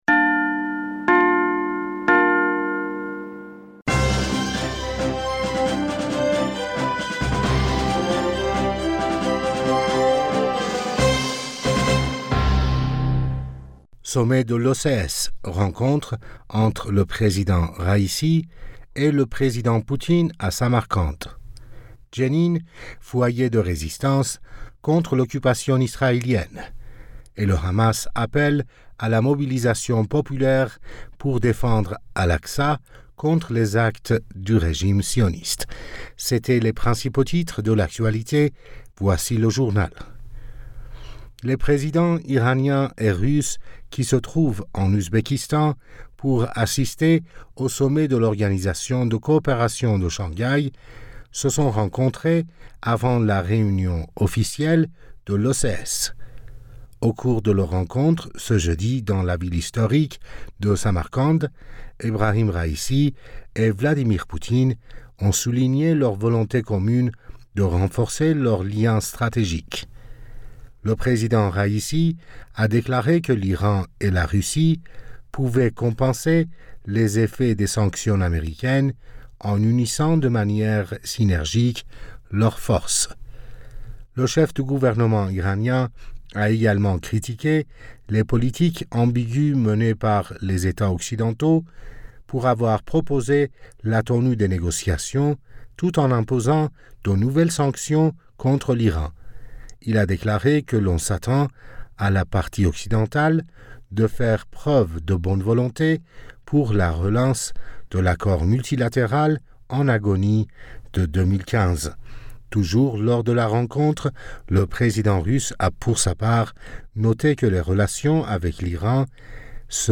Bulletin d'information Du 15 Septembre